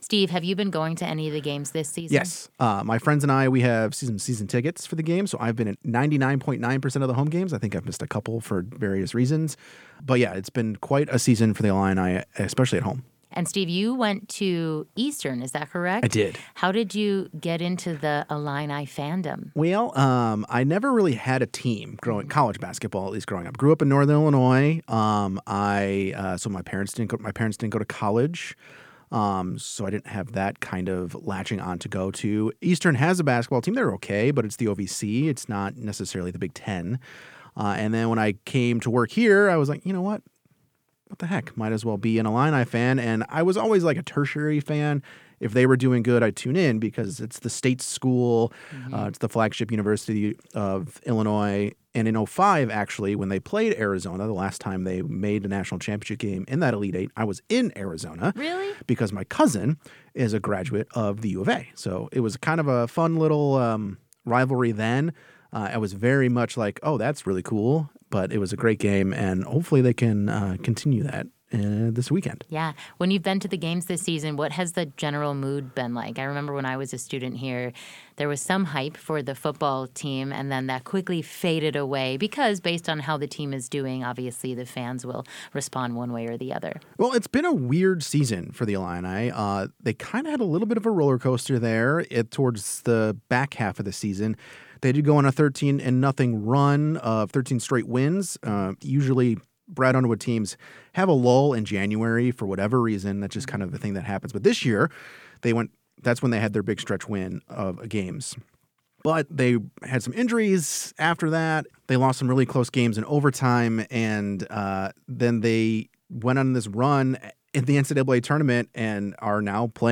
This interview has been edited for clarity and conciseness.